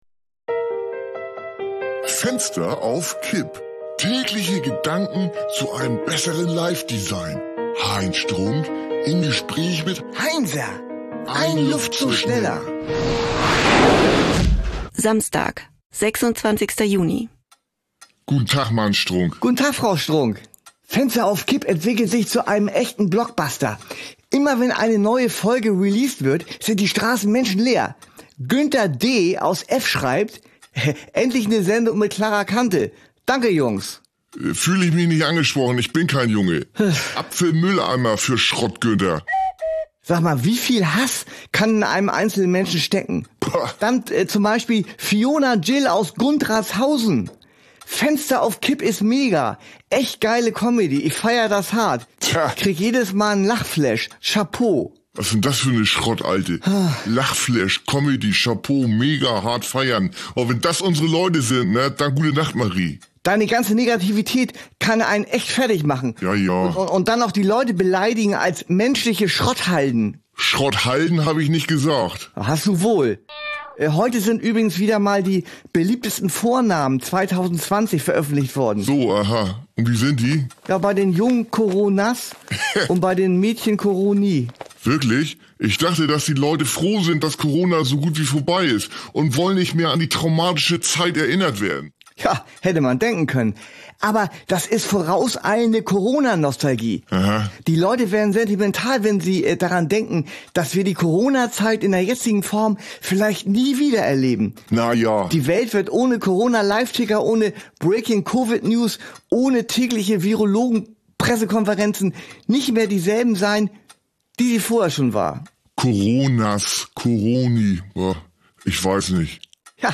eine Audio-Sitcom von Studio Bummens